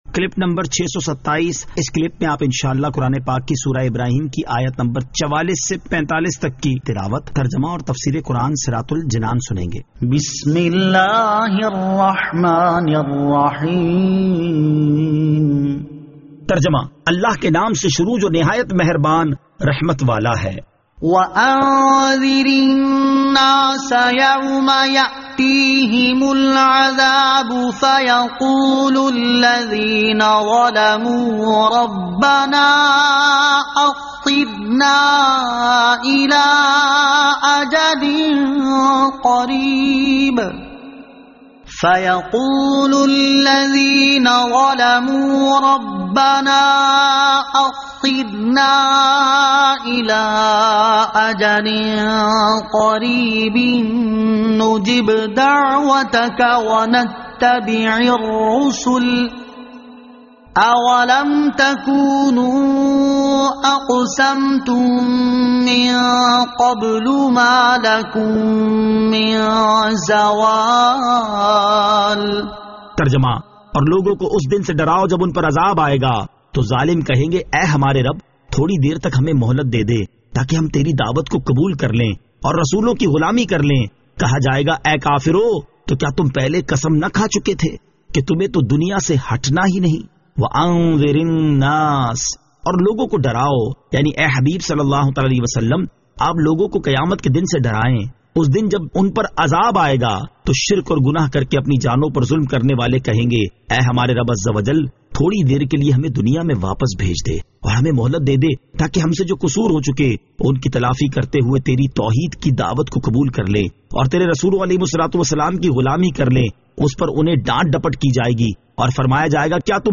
Surah Ibrahim Ayat 44 To 45 Tilawat , Tarjama , Tafseer